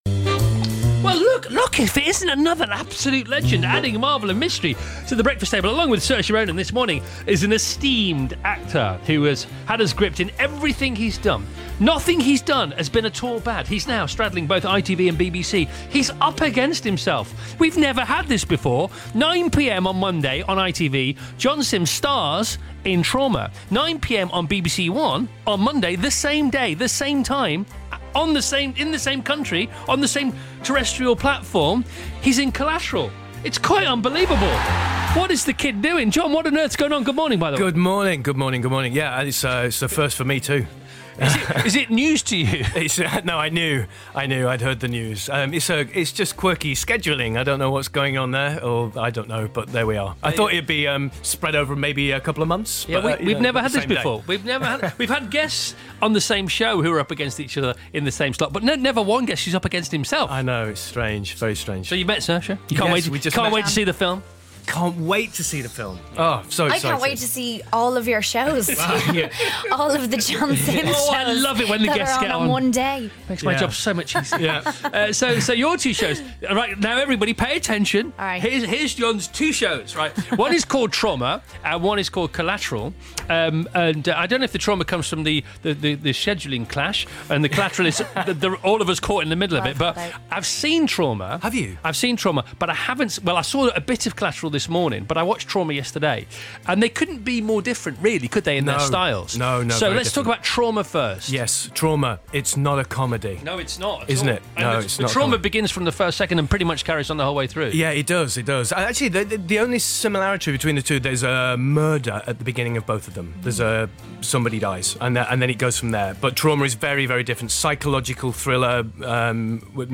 Interview: John Simm Spills the Beans with Chris Evans on BBC Radio 2